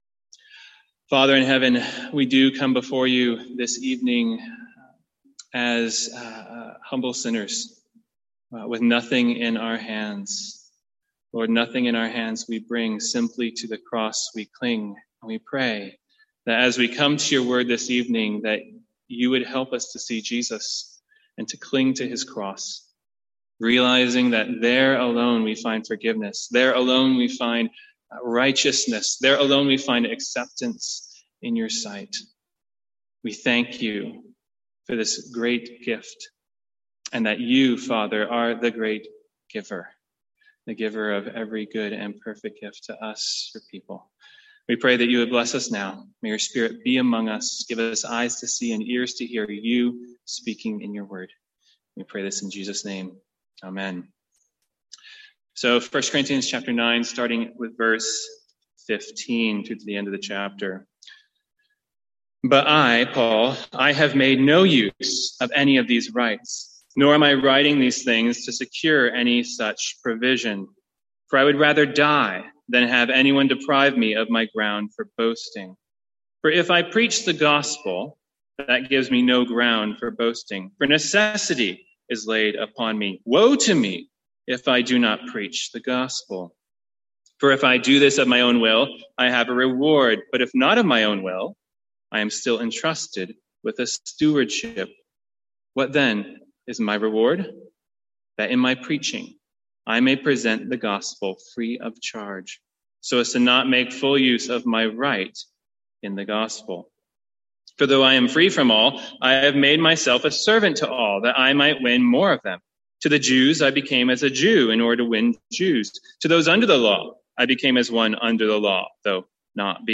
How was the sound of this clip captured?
From our evening series in 1 Corinthians.